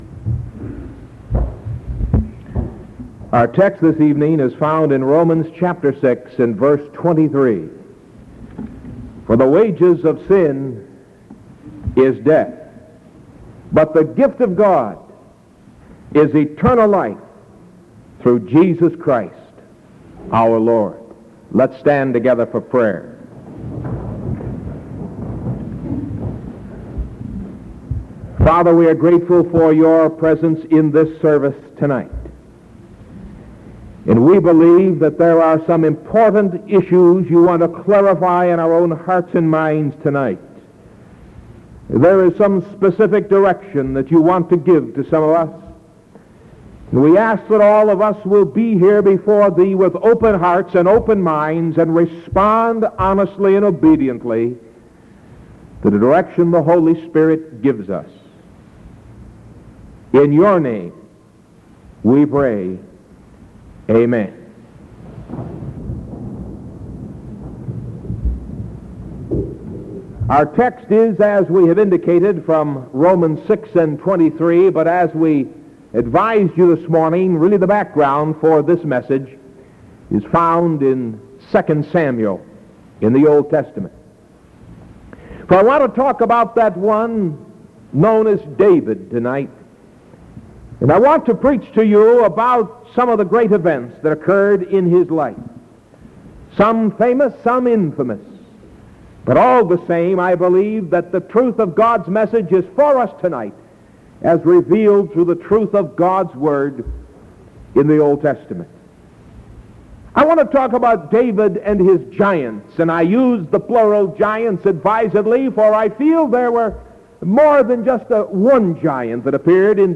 Sermon from July 15th 1973 PM